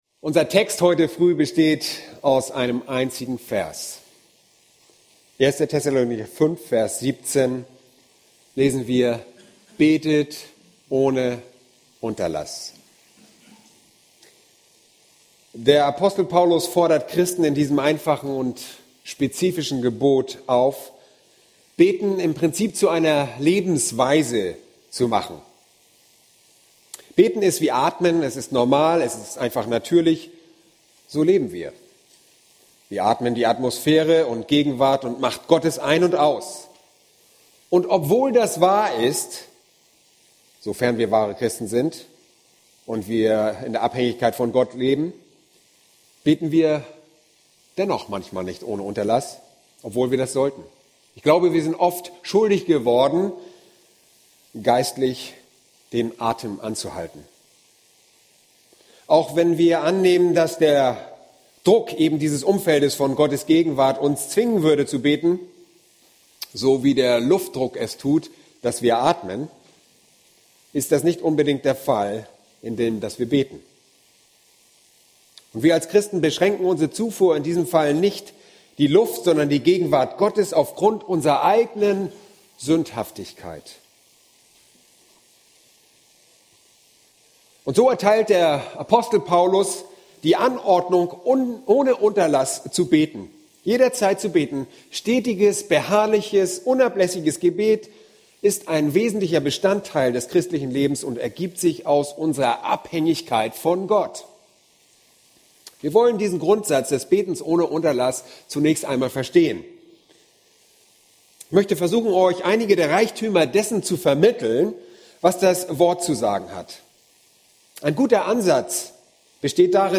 Predigten Übersicht nach Serien Startseite Predigt-Archiv Predigten Übersicht nach Serien